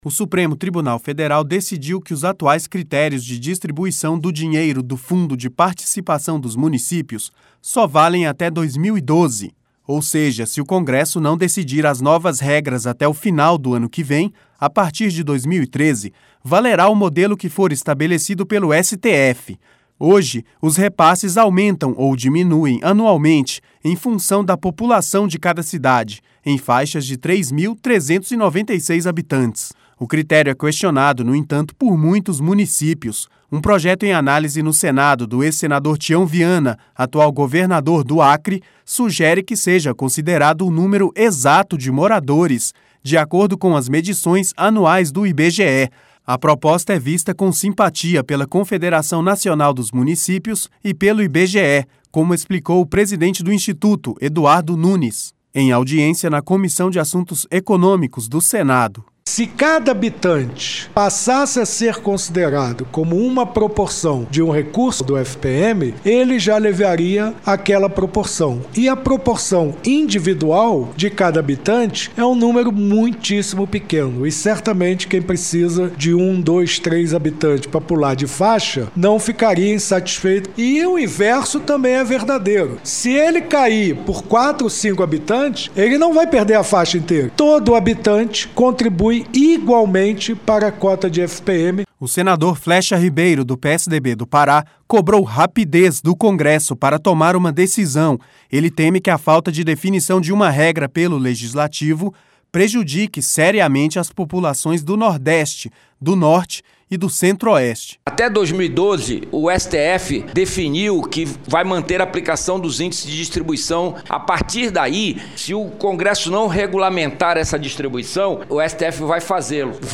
A proposta é vista com simpatia pela Confederação Nacional dos Municípios e pelo IBGE, como explicou o presidente do instituto, Eduardo Nunes, em audiência na Comissão de Assuntos Econômicos do Senado.